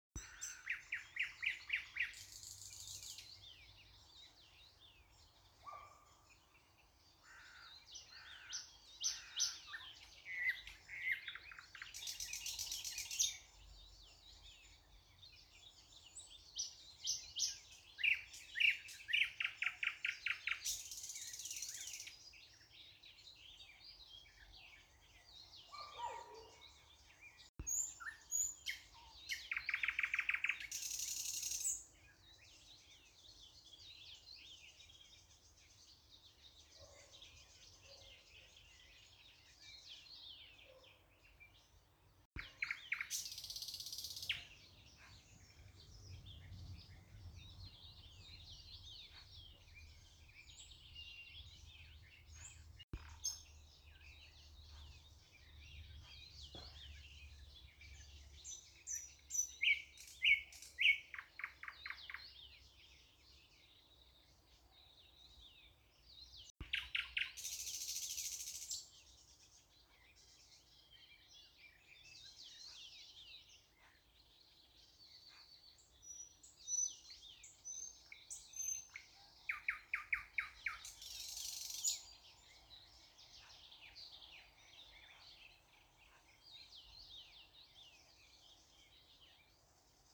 соловей, Luscinia luscinia
Administratīvā teritorijaRīga
СтатусПоёт